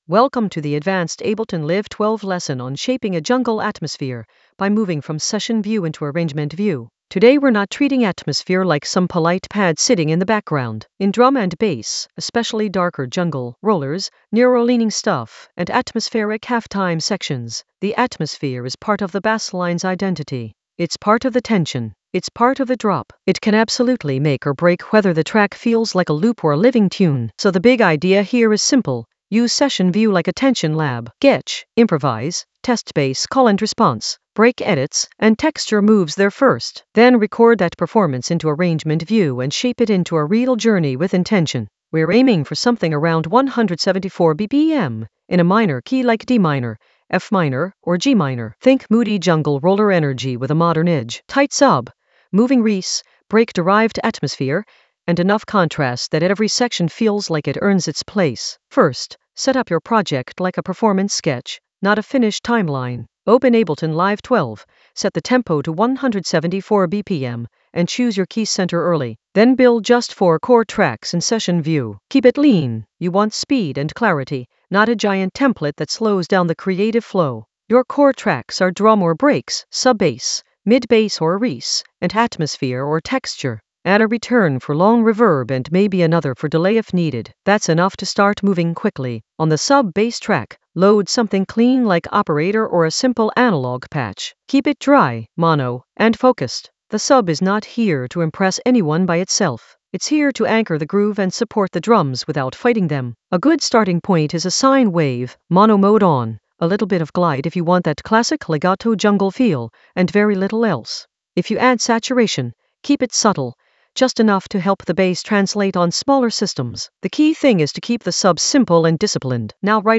An AI-generated advanced Ableton lesson focused on Shape jungle atmosphere using Session View to Arrangement View in Ableton Live 12 in the Basslines area of drum and bass production.
Narrated lesson audio
The voice track includes the tutorial plus extra teacher commentary.